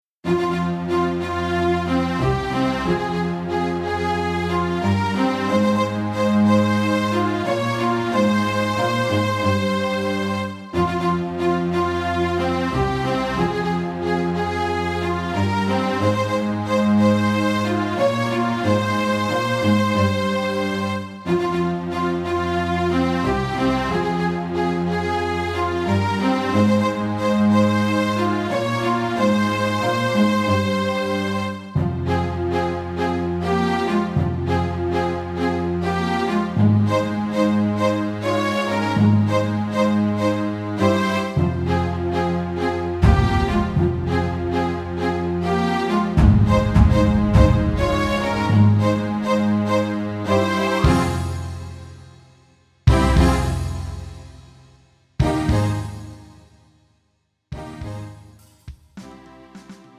음정 원키
장르 가요 구분 Pro MR